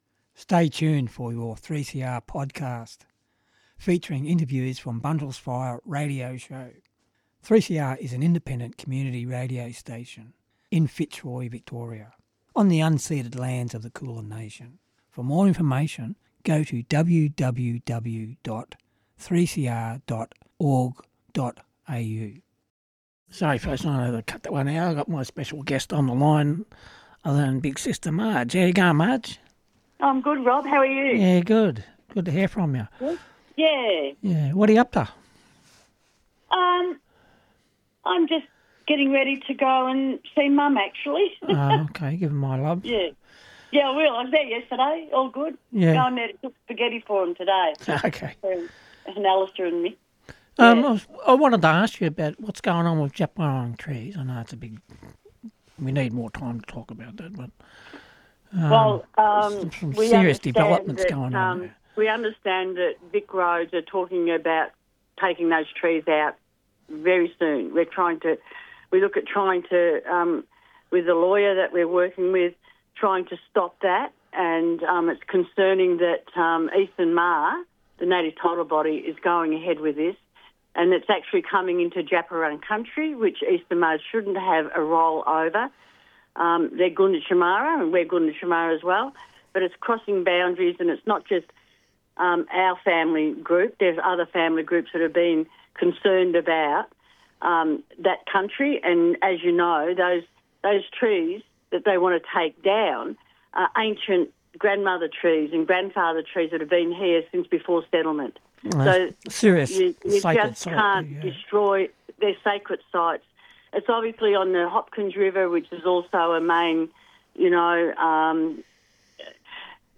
The show also includes the following interviews and excerpts: